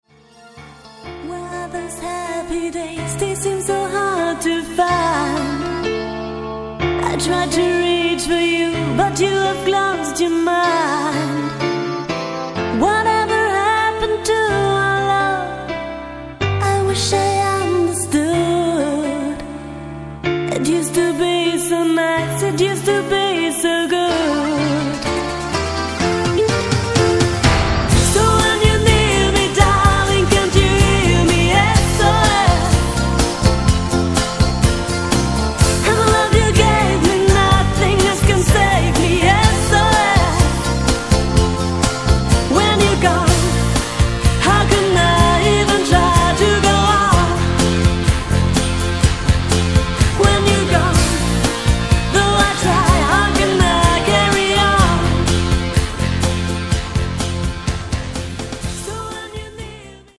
Vocals, Bass, Keys, Trumpet
Guitar, Trombone
Drums